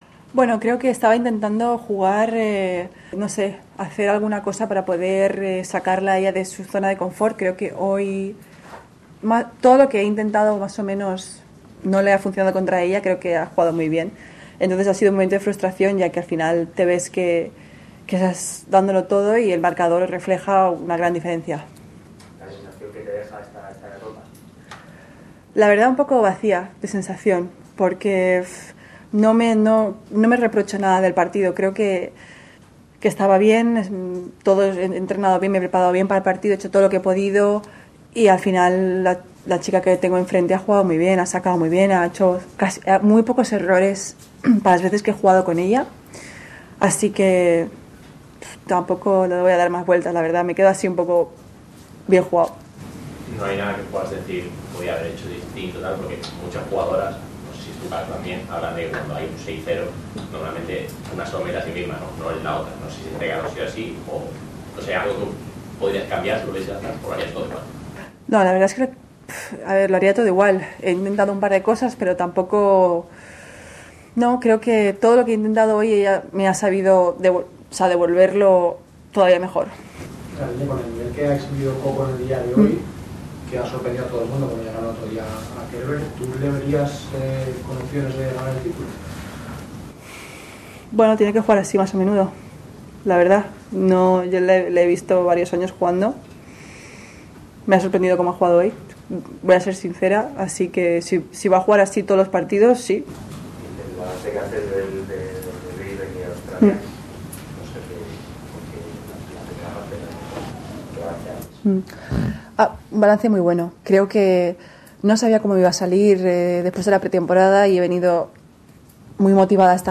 La estadounidense Coco Vandeweghe, número 35 del mundo, frenó este martes las ambiciones de la española Garbiñe Muguruza de avanzar en Australia y se clasificó para semifinales. Escucha aquí la entrevista con Garbiñe.